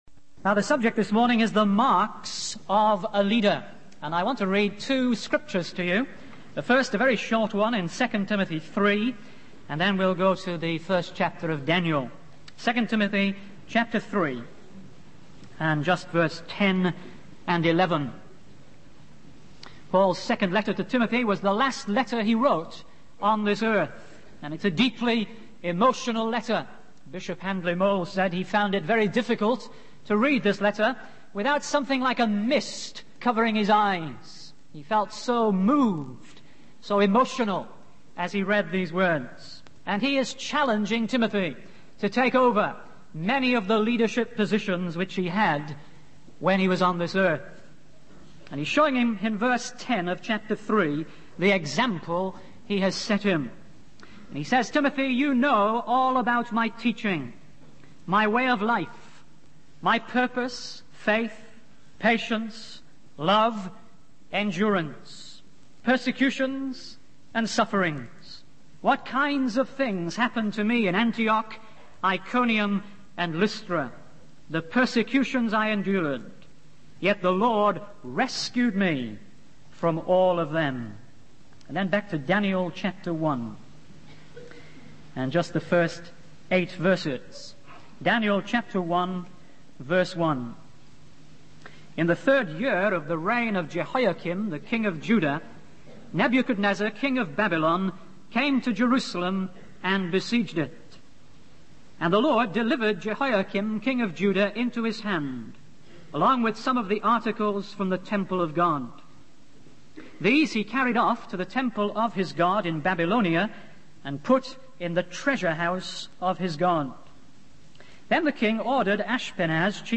In this sermon, the speaker discusses four key signs of God's gifted leaders. The first sign is the ability to draw others to follow them, which is achieved through a deep love of life and a commitment to truth.